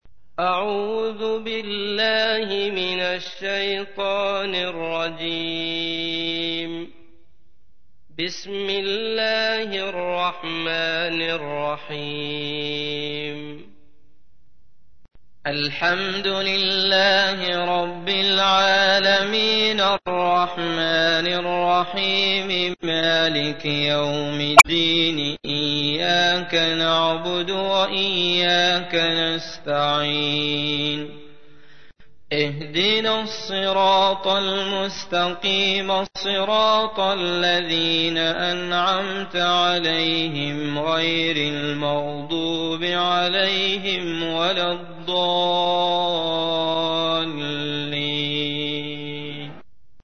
تحميل : 1. سورة الفاتحة / القارئ عبد الله المطرود / القرآن الكريم / موقع يا حسين